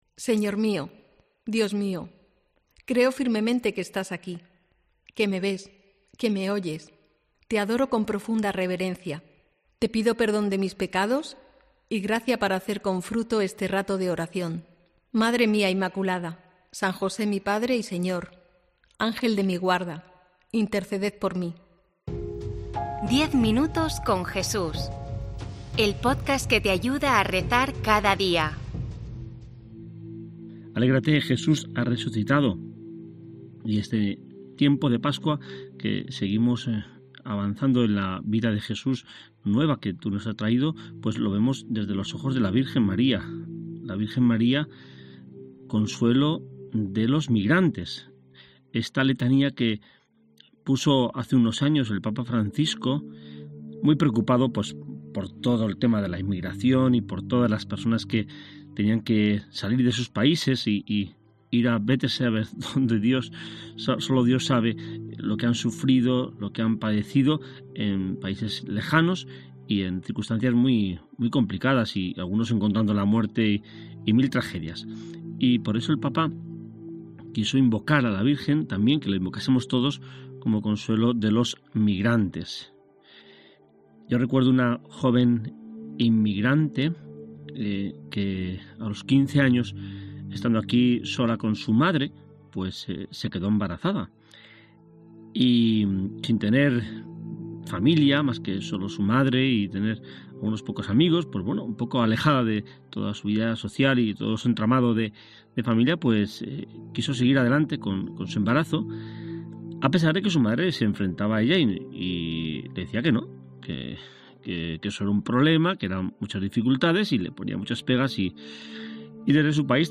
AUDIO: COPE incorpora a su oferta de podcats '10 minutos con Jesús', una meditación diaria en formato podcast centrada en el Evangelio,...